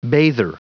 Prononciation du mot bather en anglais (fichier audio)
Prononciation du mot : bather